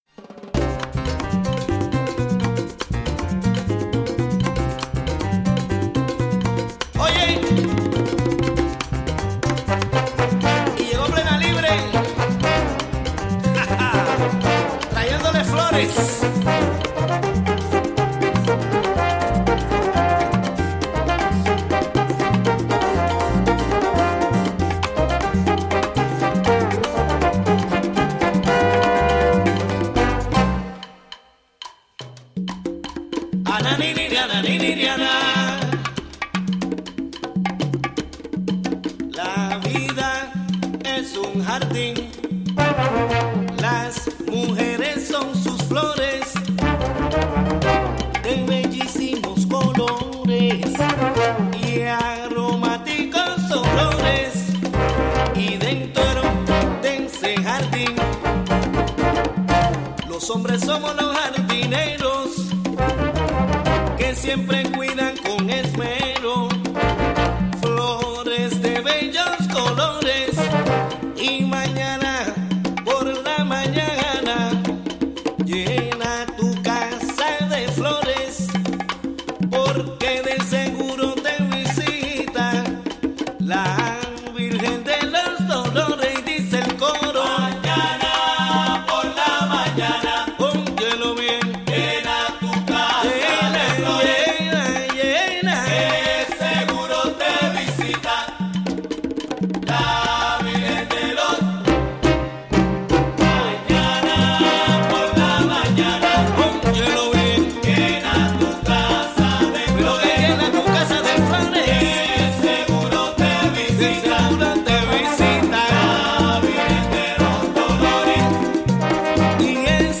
11am Un programa imperdible con noticias, entrevistas,...
In this broadcast, a conversation about the visit of U.S. Rep. Sean Maloney (D-NY 18th District) and U.S. Representative Ben Ray Luján (D-NM 3rd District) to Newburgh and their conversation with Latino community leaders.